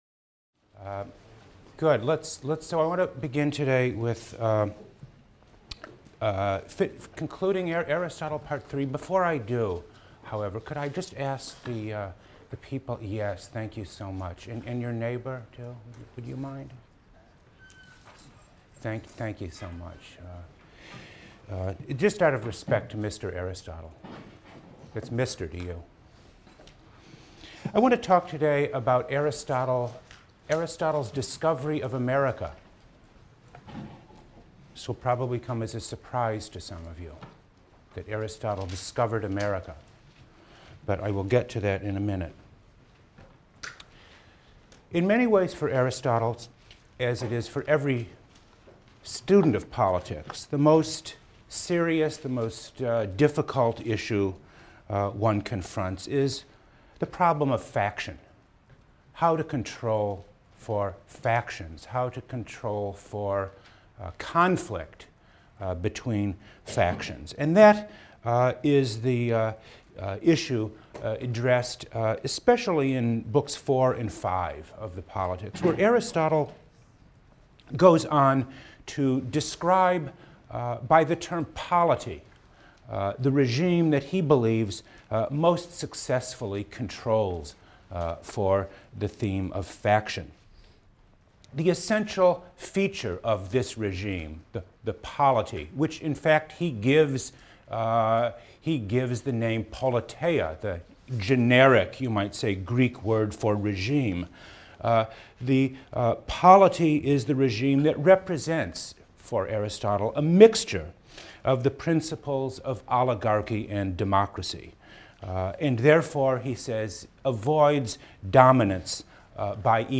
PLSC 114 - Lecture 9 - The Mixed Regime and the Rule of Law: Aristotle, Politics, VII | Open Yale Courses